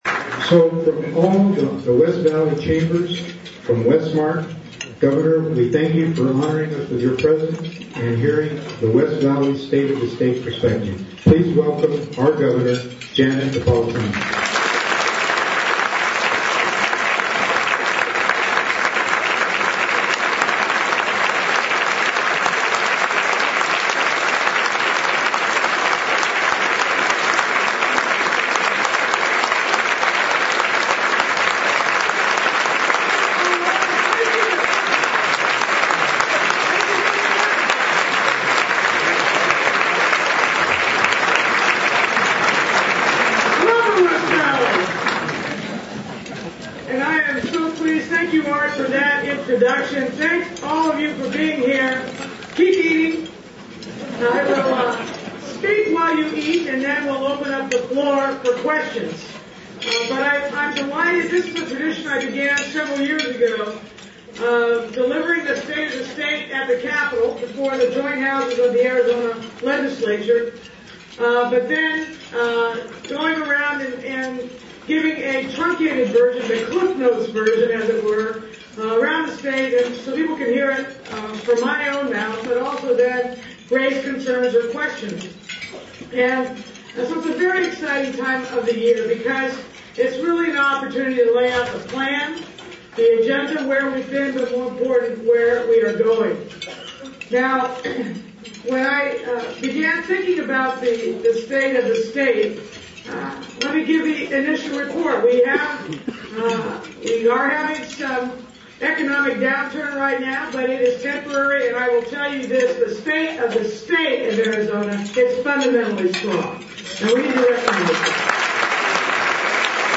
A luncheon was held with Governor Napolitano, community leaders, including Glendale Mayor Elaine Scruggs and members of the Glendale City Council, West Valley business owners and residents. At the lunch, the Governor presented her vision for this year and provide input on activities taking place on the West Valley and throughout the state.
gov state of state 2008audio.mp3